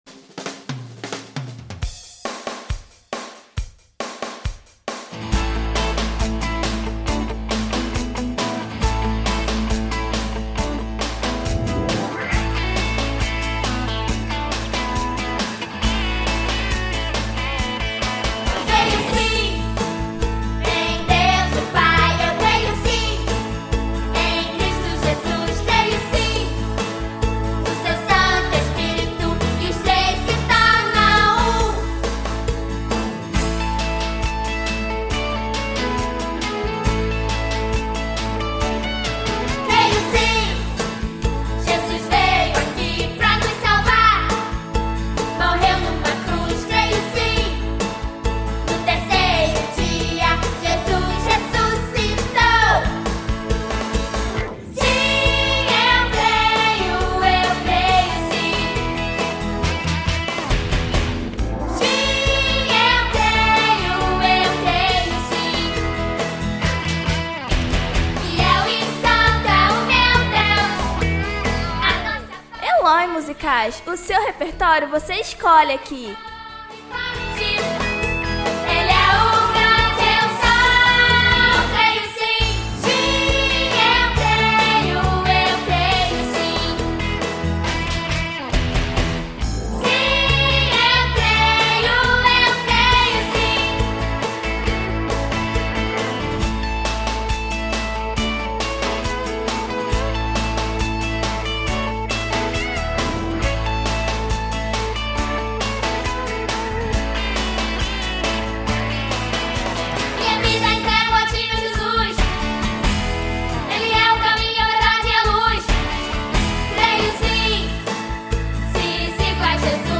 Instrumentos de “batucada” estão ao seu redor.